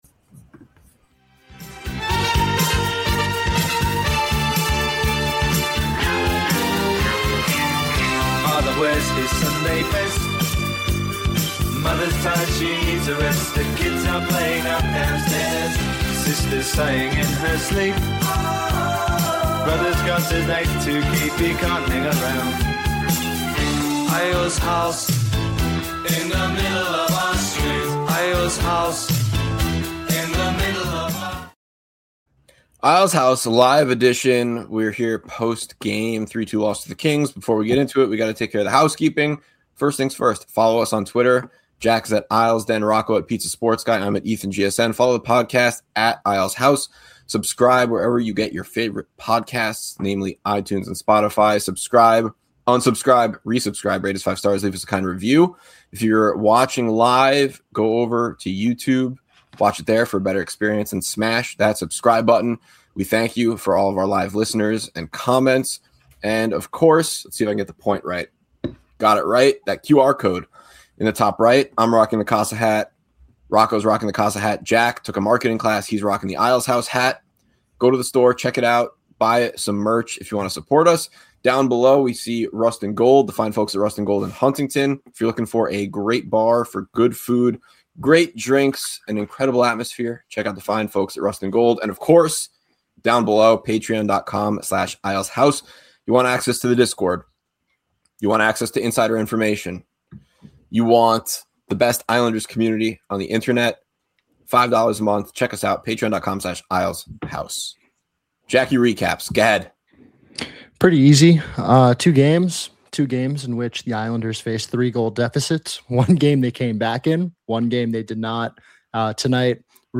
Isles House Postgame Live Stream